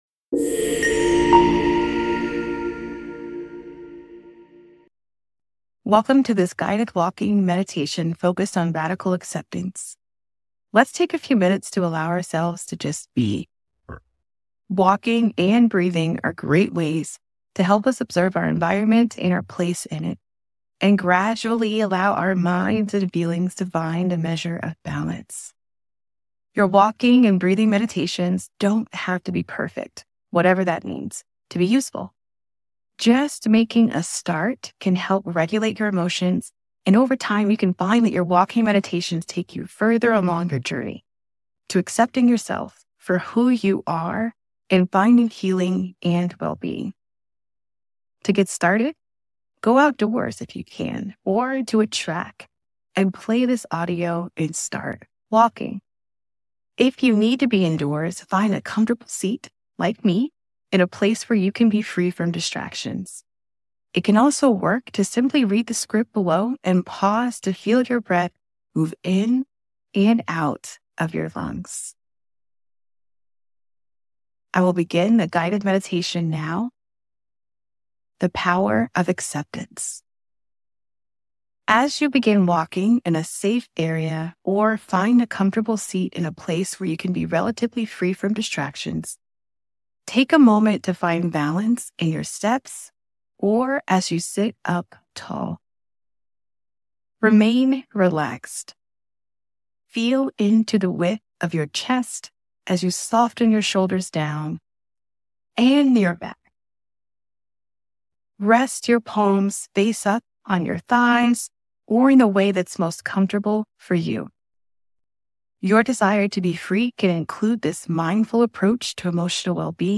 Guided Walking Meditation: Justice and Radical Acceptance - The Steve Fund